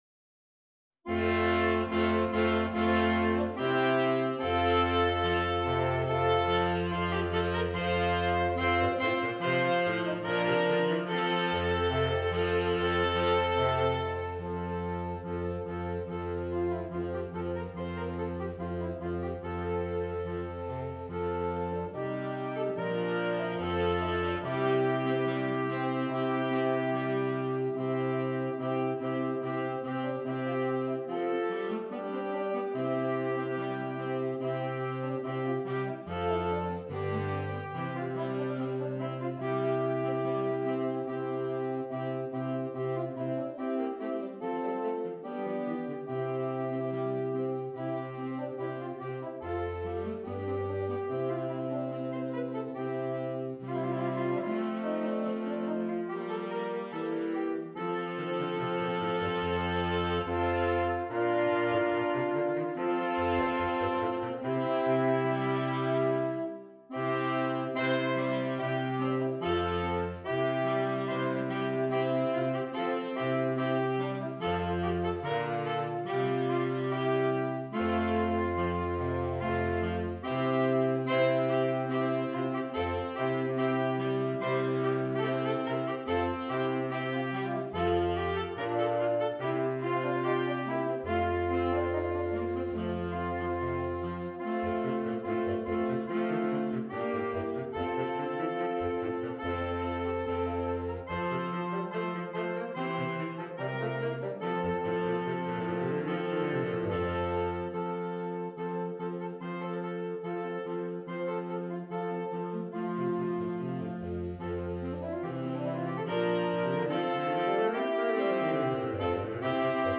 Saxophone Quartet (SATB or AATB)